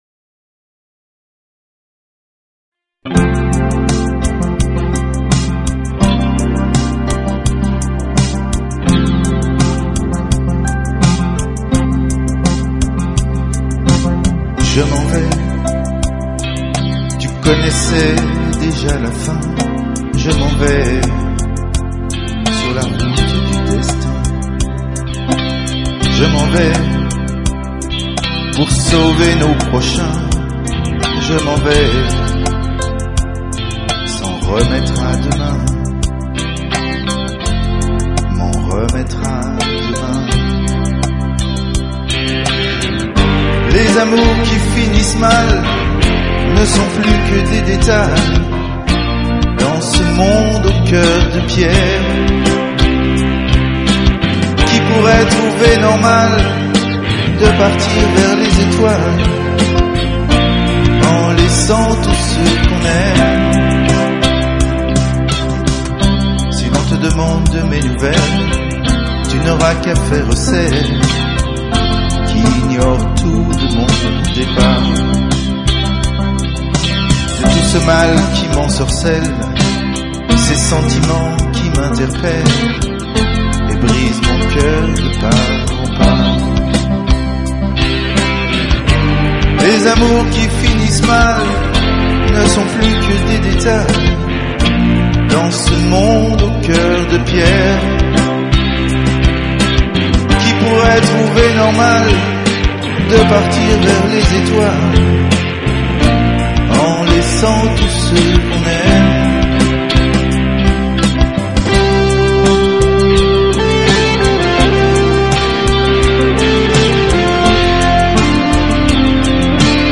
voix, claviers, guitares,basse et programmation batterie
La Fiction pop-rock